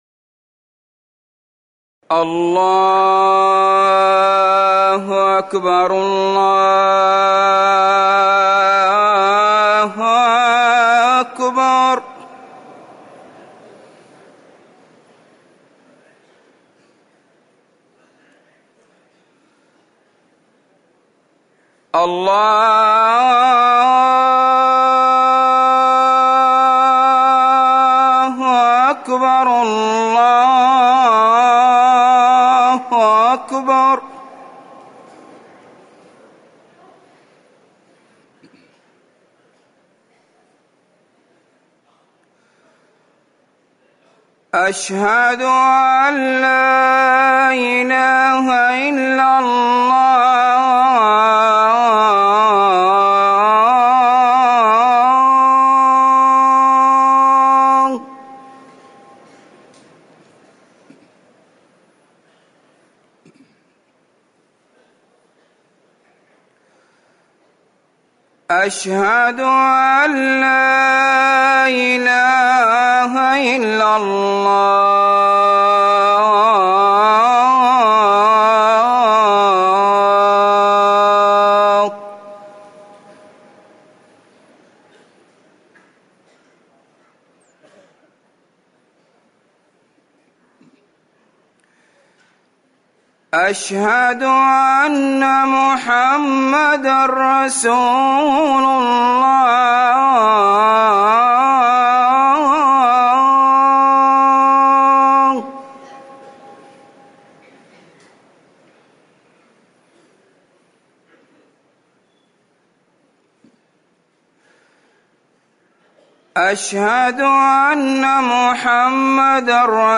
أذان الظهر - الموقع الرسمي لرئاسة الشؤون الدينية بالمسجد النبوي والمسجد الحرام
تاريخ النشر ١ محرم ١٤٤١ هـ المكان: المسجد النبوي الشيخ